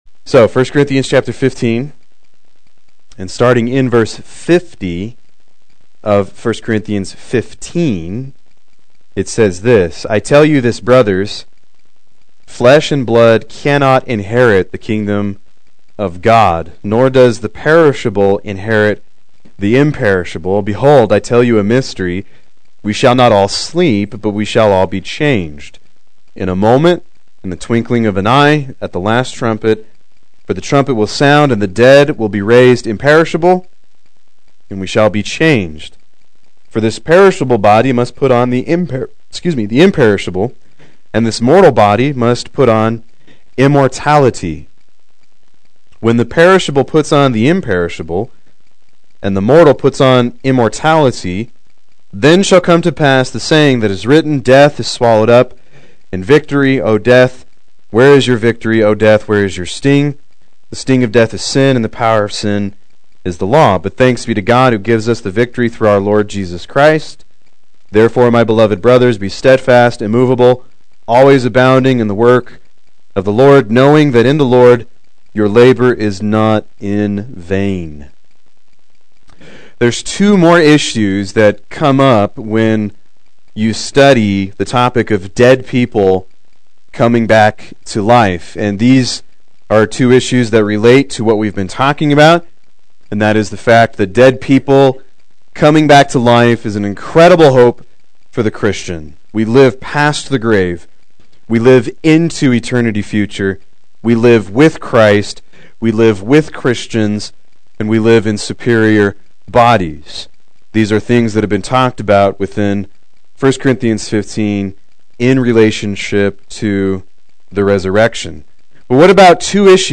Proclaim Youth Ministry - 12/10/16
Play Sermon Get HCF Teaching Automatically.